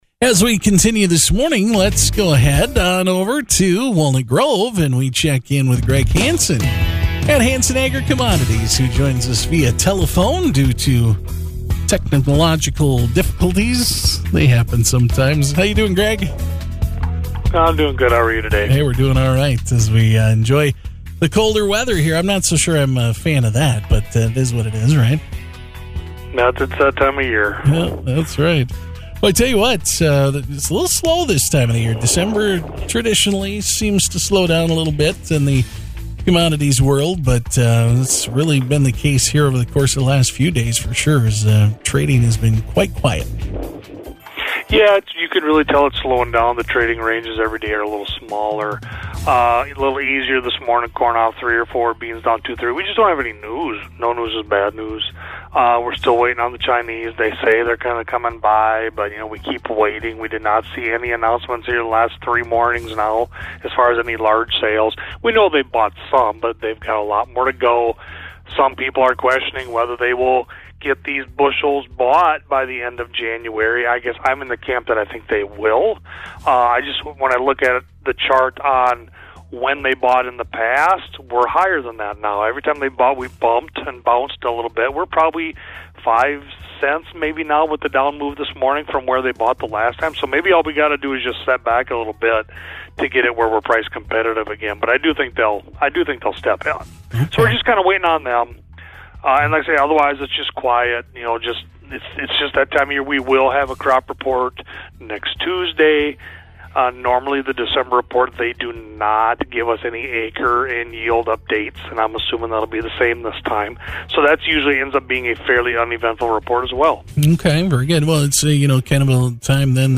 12-3-25 Live on KMHL Marshall Radio
12-3-LIVE-CALL.mp3